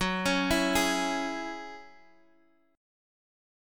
Gb7sus2 Chord